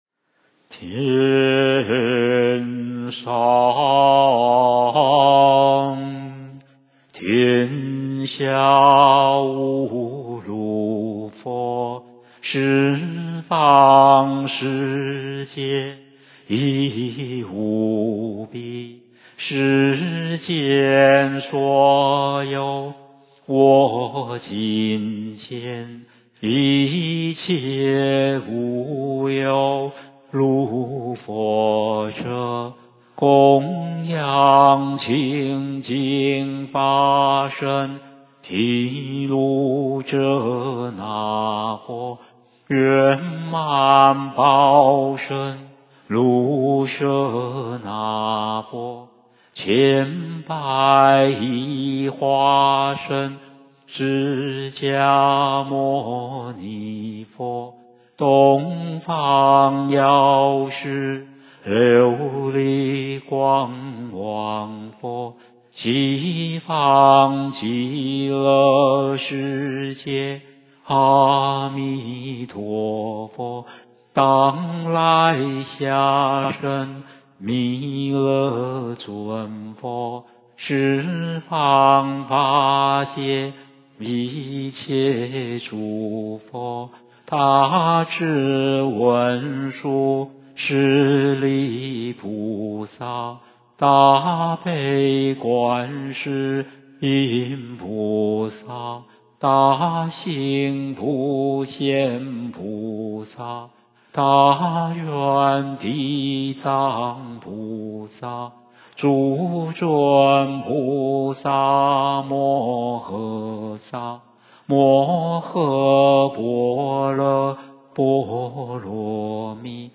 梵呗教学音档